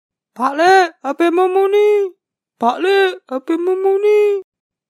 Nada dering suara Jawa
Kategori: Nada dering
nada-dering-suara-jawa-id-www_tiengdong_com.mp3